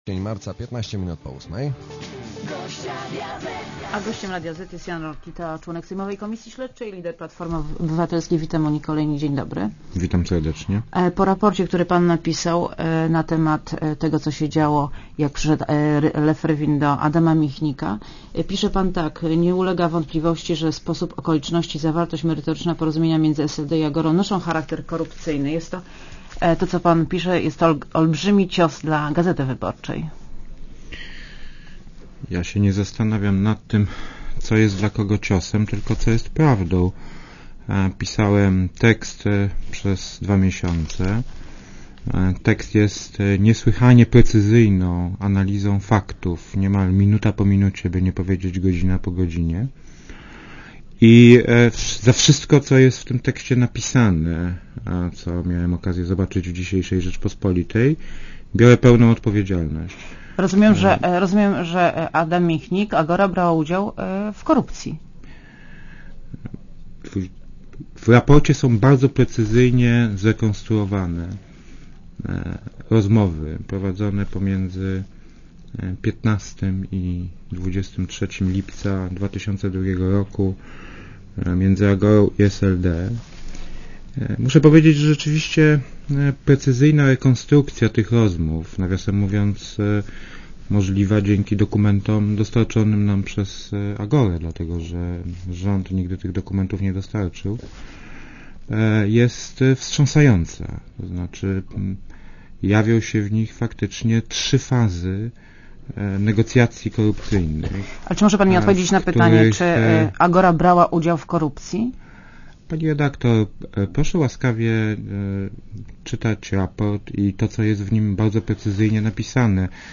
Gościem Radia Zet jest Jan Rokita, członek sejmowej komisji śledczej i lider Platformy Obywatelskiej.